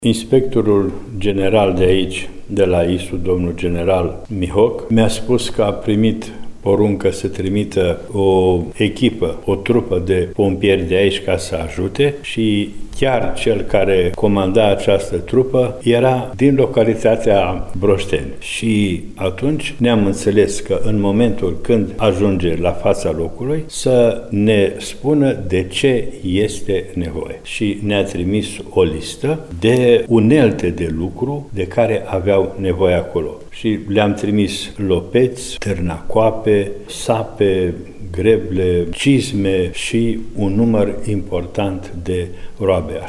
Înalpreasfinția sa Ioan, mitropolitul Banatului, spune că a dorit să vină în sprijinul pompierilor cu unelte.